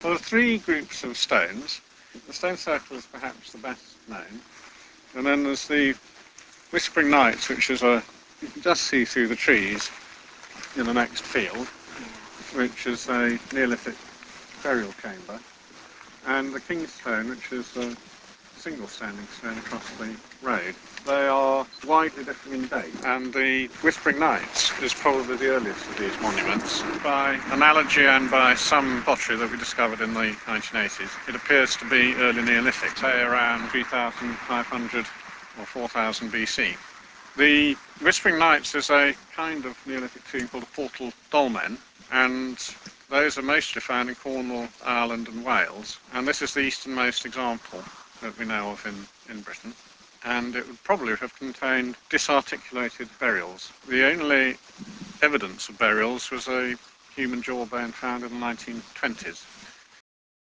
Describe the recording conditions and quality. In 1997 the regulars of the Stones Mailing List and their friends gathered at the Rollright Stones for a get-together, the first of many as it turned out.